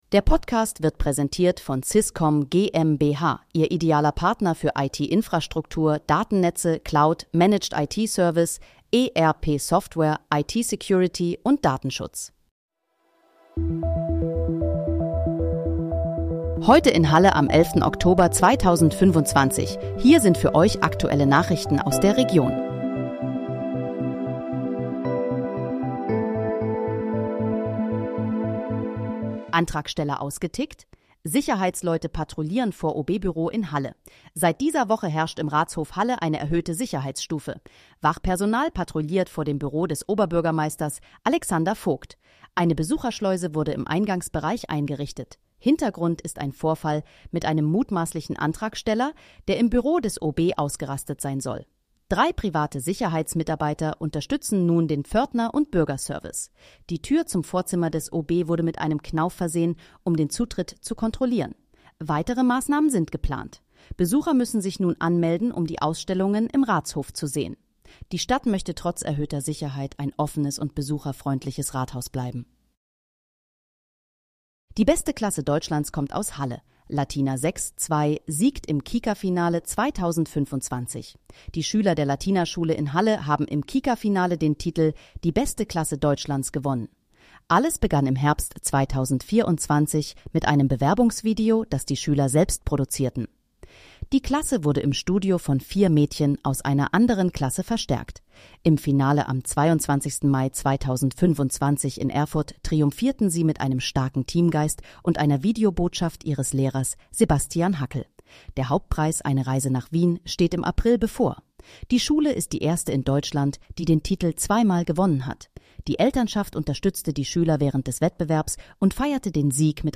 Heute in, Halle: Aktuelle Nachrichten vom 11.10.2025, erstellt mit KI-Unterstützung
Nachrichten